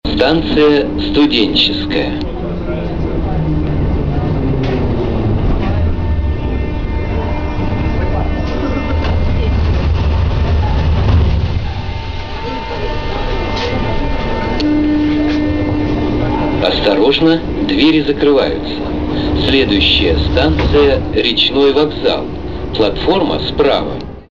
Звуки метро